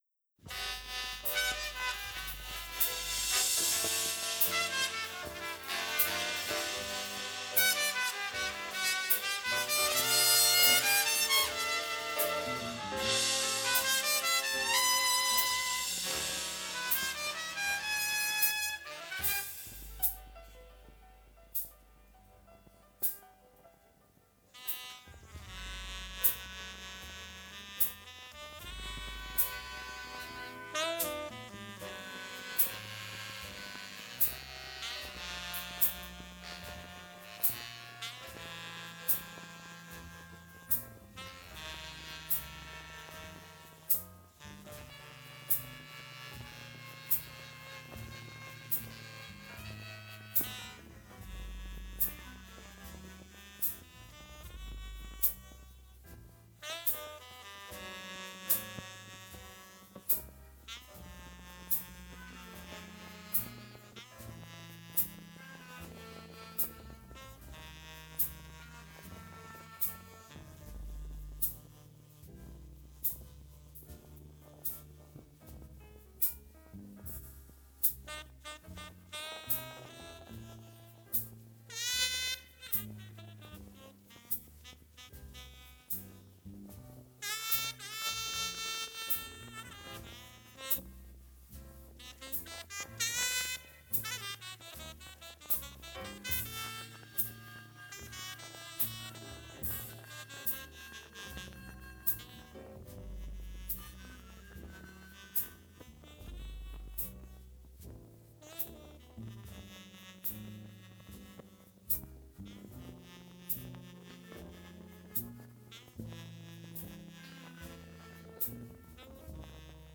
That is very low.